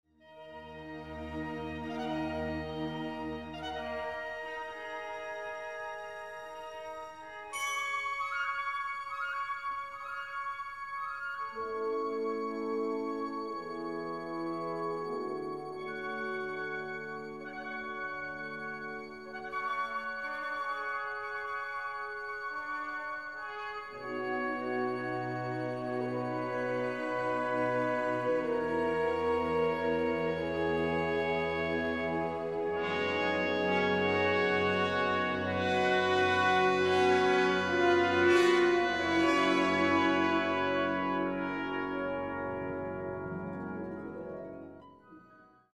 Początek jest mroczny, powolny i bardzo przestrzenny. Wykonanie jest interesujące, niestety od początku staje się jasne, że dźwięk tej rejestracji jest jasny, dość cienki, cichy i w zasadzie pozbawiony rejestru basowego.
Piękne i doskonale wyczute są zwłaszcza grane poetycko i delikatnie fragmenty liryczne: